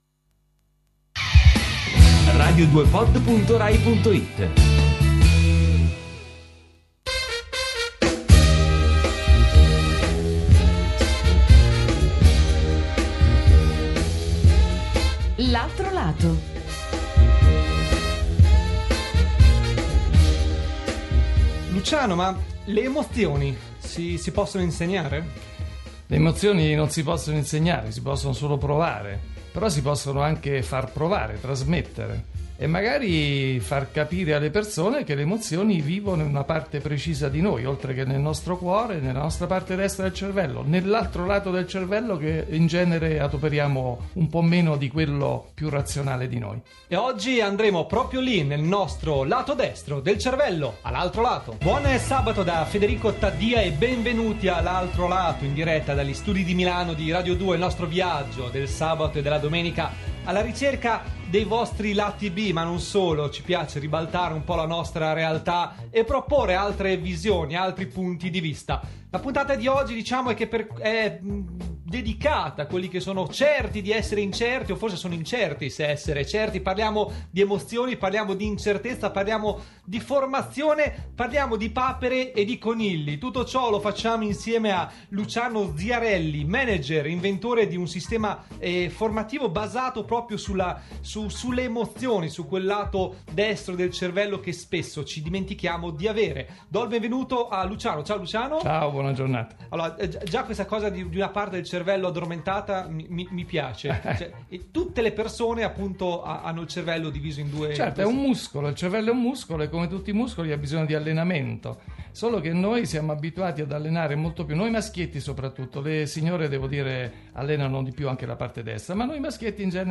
Intervista web-marketing
intervista.mp3